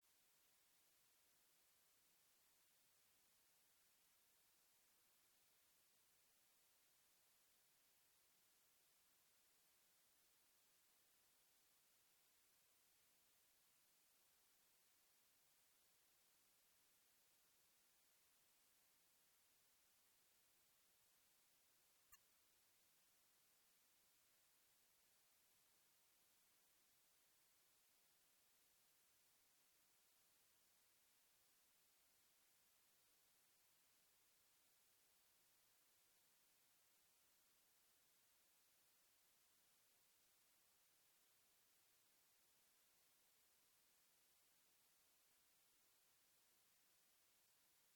Contes et légendes chantés